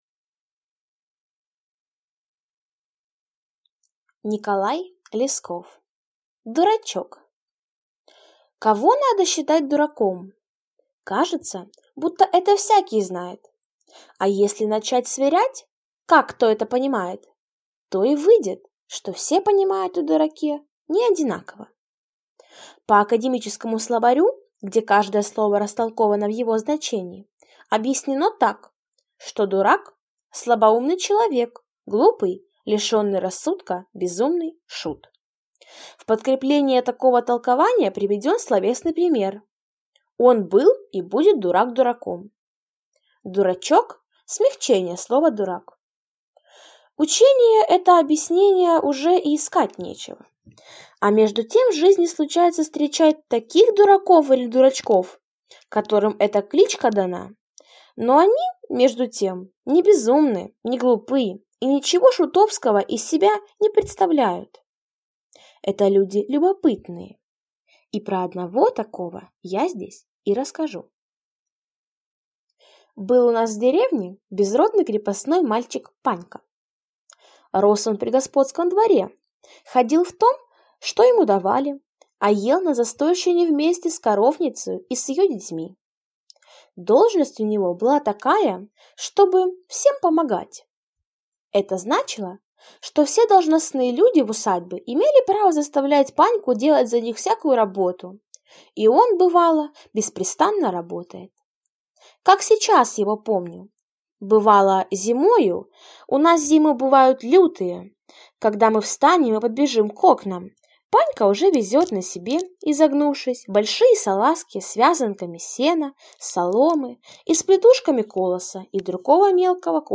Aудиокнига Дурачок